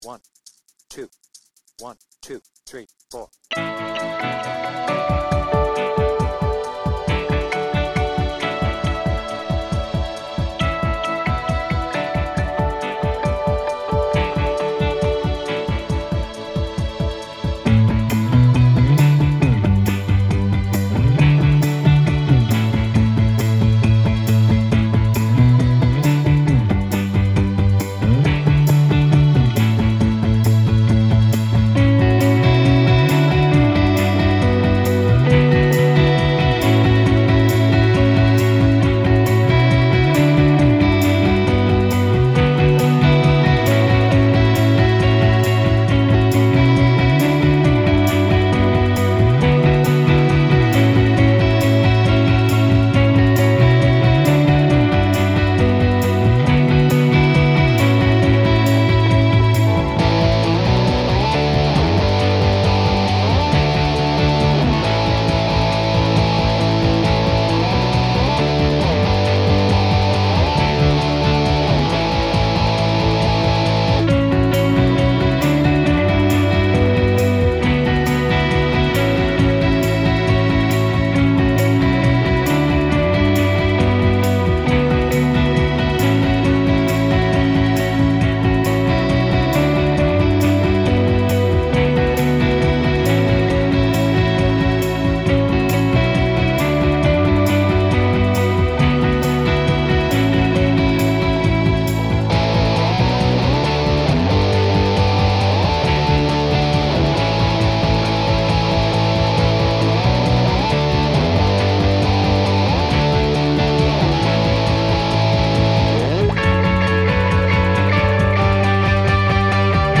BPM : 136
Without vocals